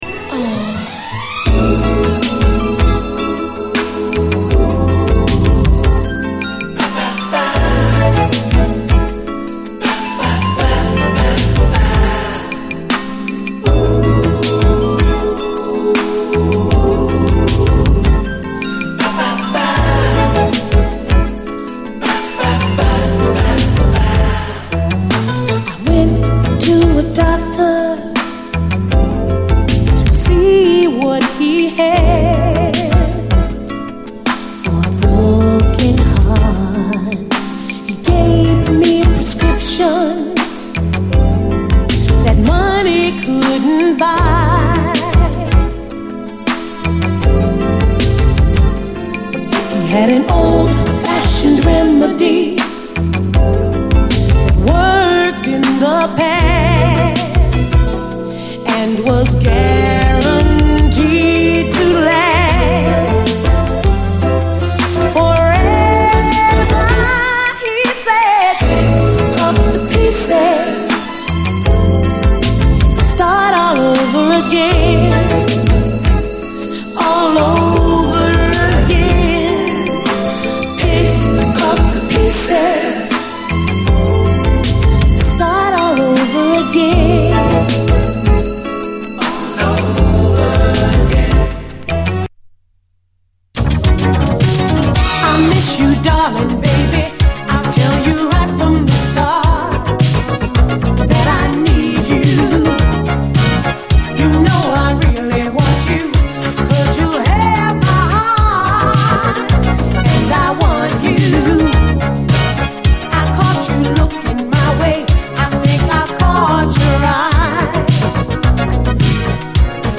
SOUL, 70's～ SOUL, DISCO
UKソウル・フィーリングを持ったメロウ・アーバン・ソウル＆シンセ・モダン・ブギー！